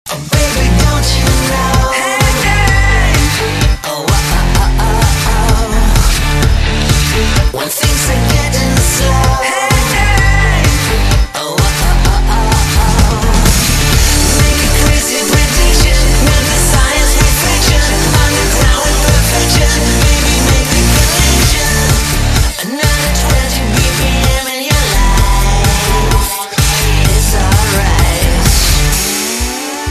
M4R铃声, MP3铃声, 欧美歌曲 70 首发日期：2018-05-14 20:21 星期一